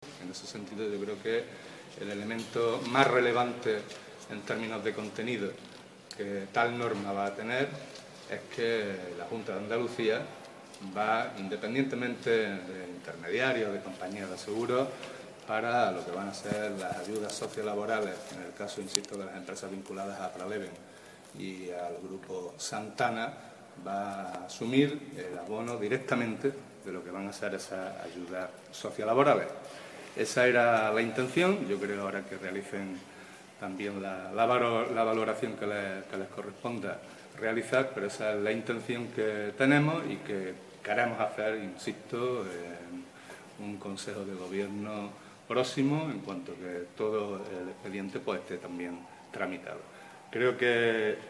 Se adjunta audio de las declaraciones realizadas por el consejero a los medios de comunicación al término de la reunión
Declaraciones Antonio Ávila. Formato .mp3